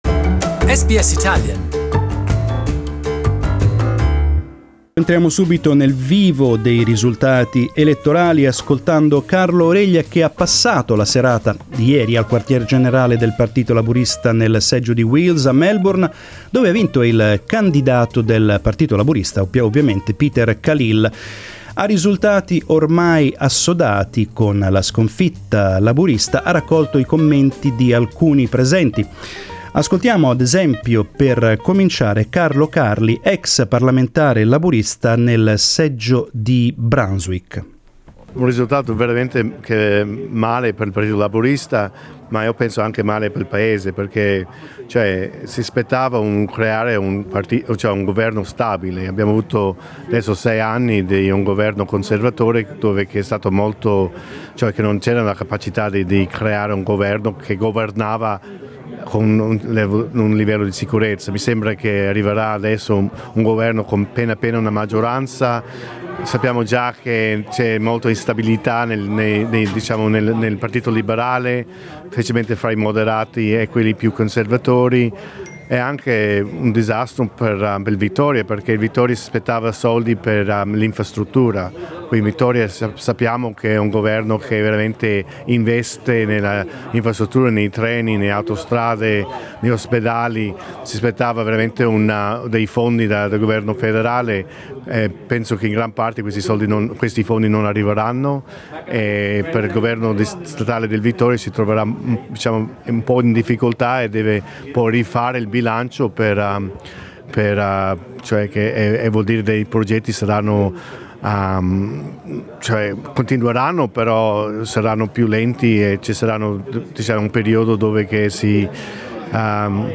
On election night we spoke to mainly Italian speakers who attended the two major parties' functions. We asked them what they thought of the result that rewarded the Coalition.